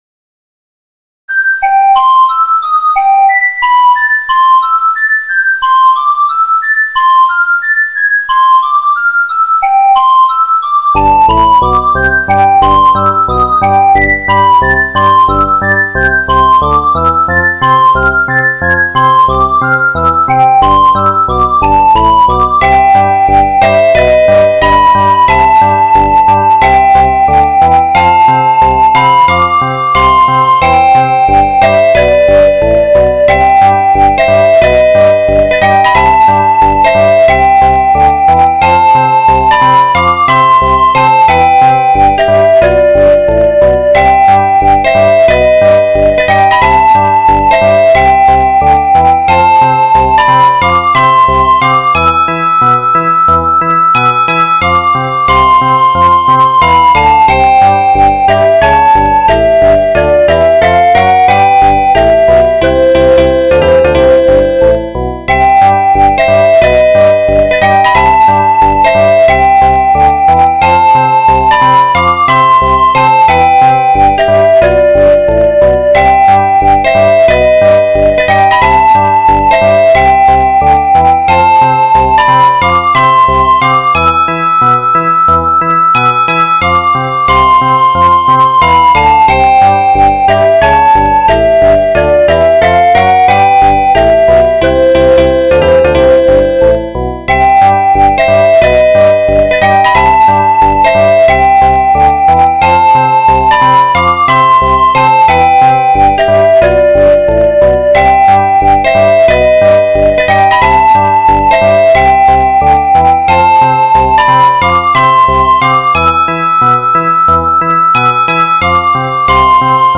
NES music covers
guitars
music cover